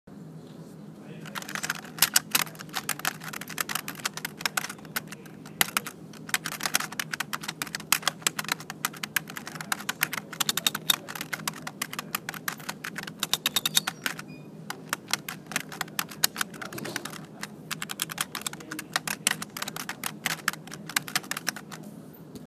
SONIDOS DE OFICINA
Ambient sound effects
sonidos_de_oficina.mp3